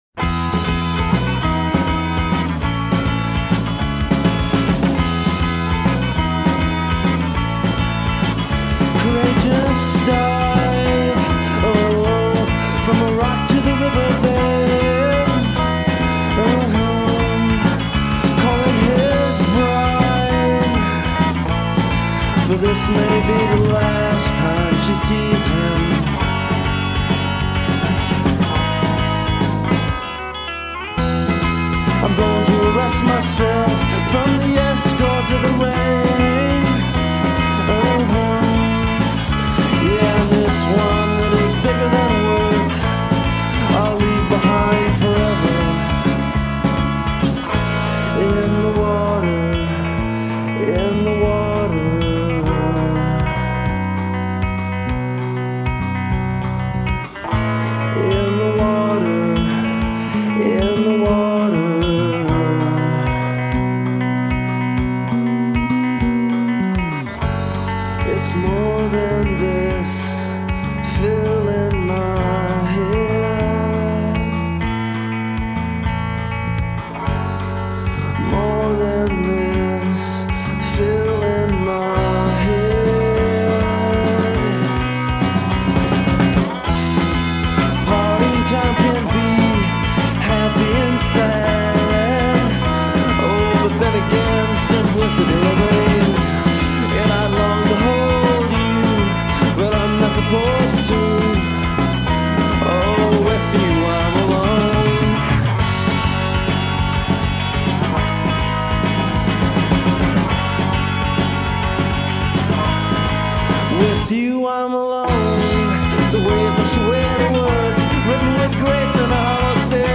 They are good guitar pop songs.
bass
drums
guitar and vocals. A trio.